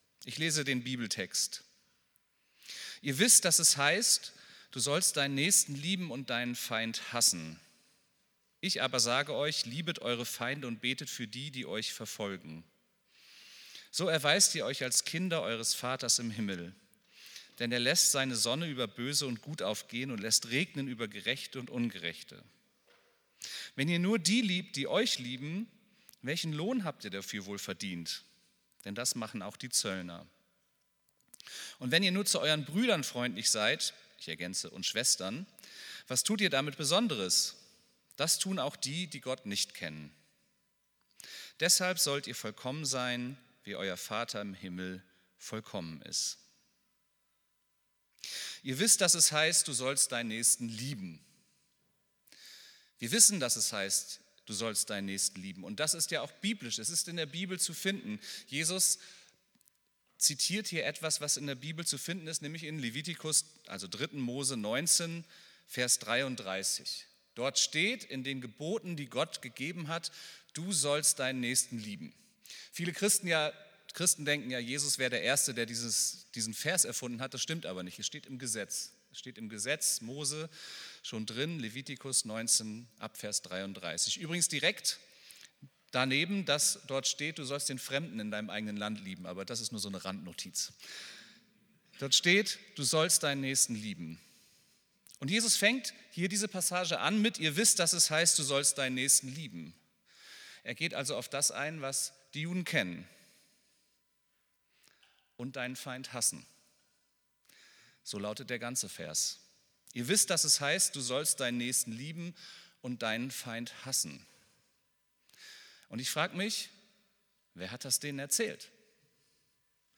Predigt vom 28.09.2025